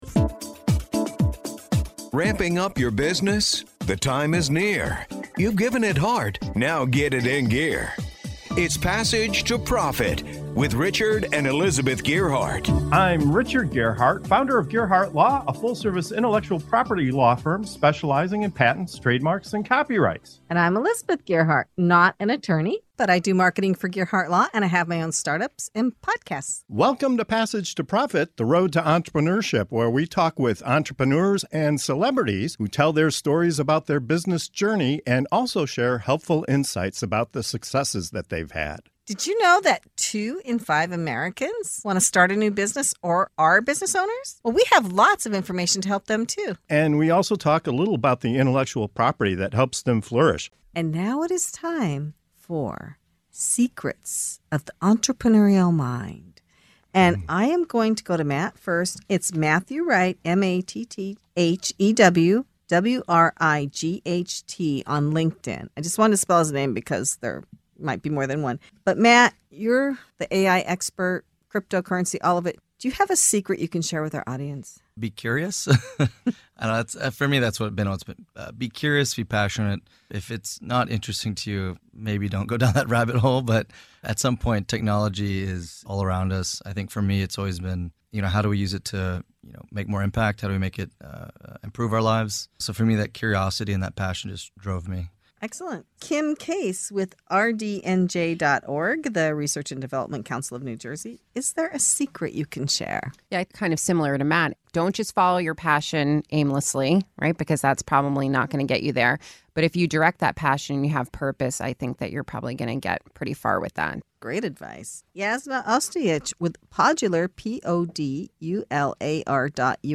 In this segment of Secrets of the Entrepreneurial Mind on Passage to Profit Show, our guests share their most powerful insights for entrepreneurial success.